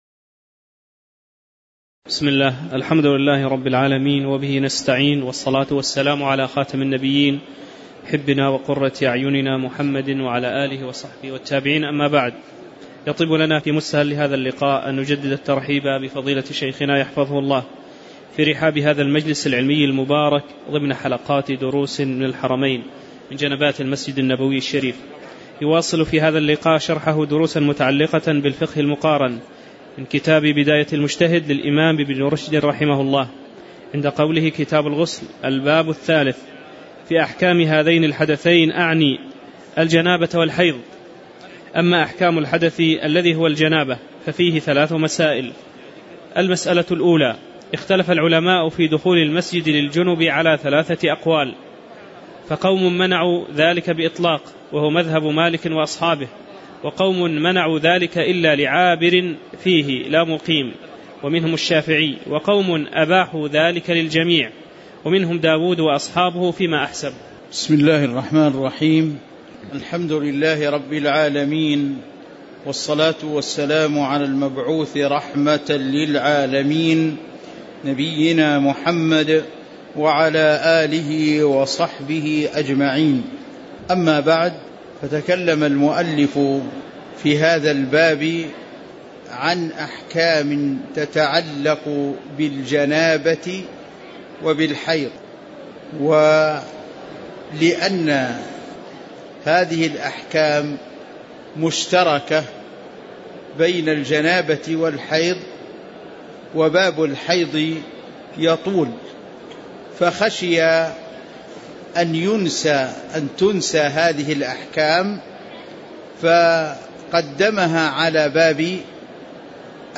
تاريخ النشر ٢٤ ربيع الأول ١٤٤٠ هـ المكان: المسجد النبوي الشيخ